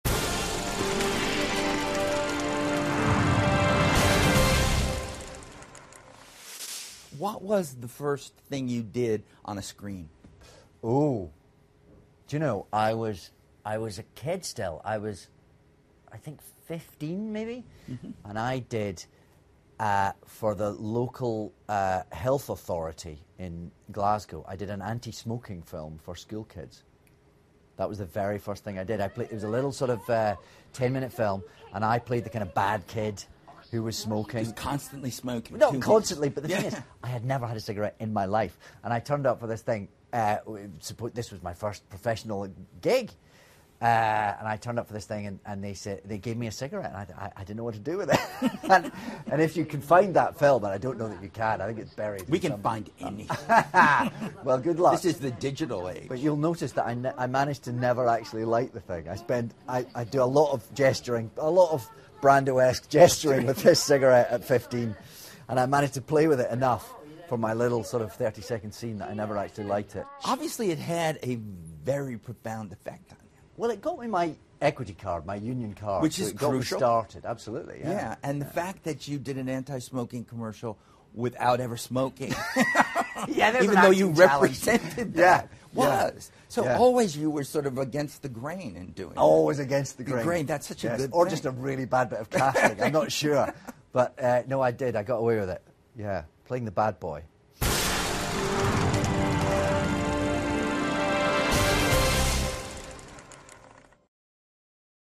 访谈录 “神秘博士”大卫·田纳特专访 听力文件下载—在线英语听力室